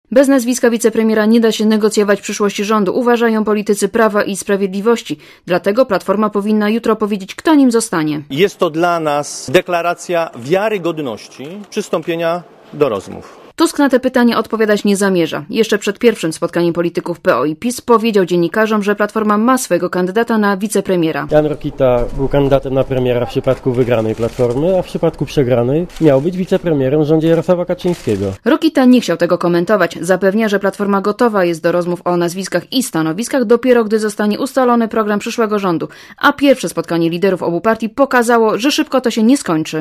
reporterki Radia ZET